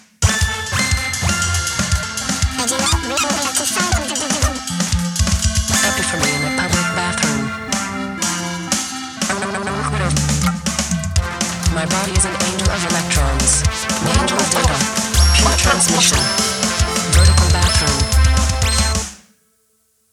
grindcore, hardcore, experimental, midicore, chiptune, ,